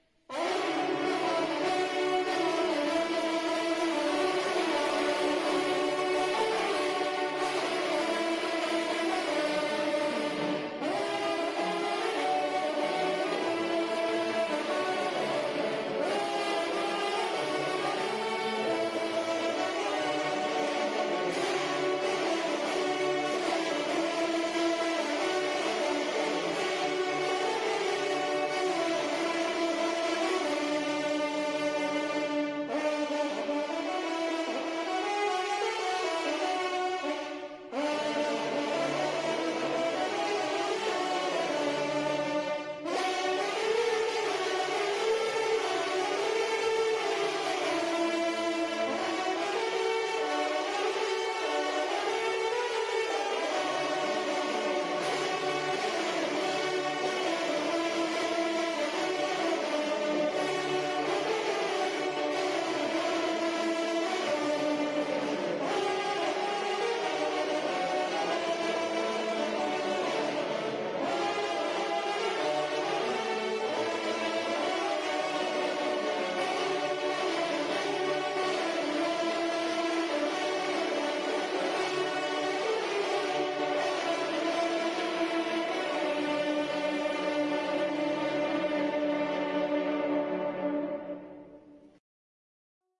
Au-Cerf-Va-Aux_Concert-Vouziers_Le-Chant-Final.mp3